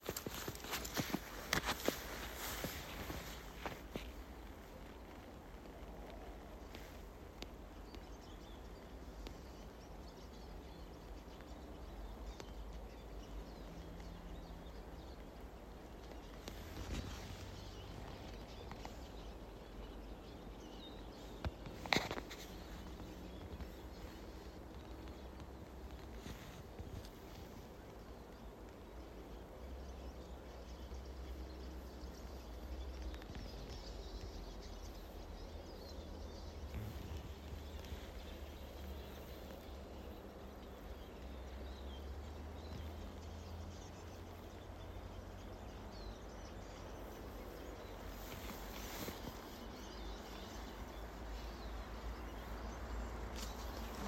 Птицы -> Вьюрковые ->
чиж, Spinus spinus